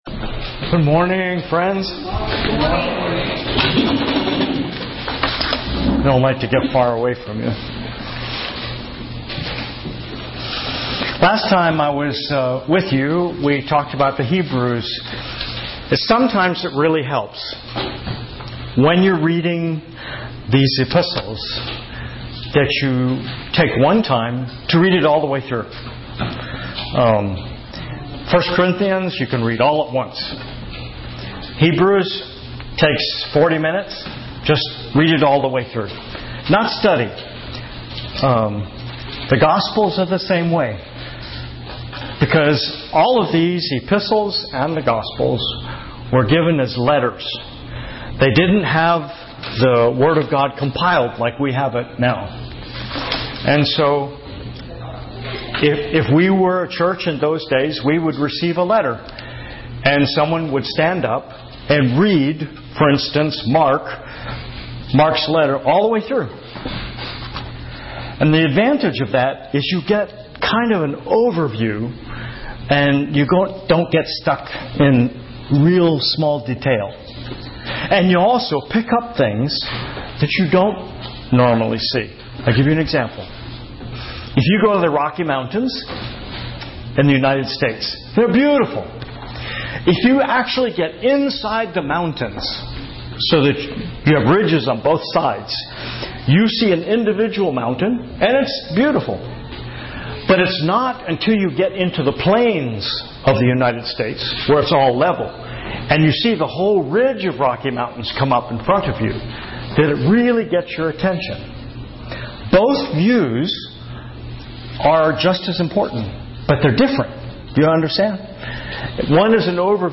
英文信息: 保羅的榜樣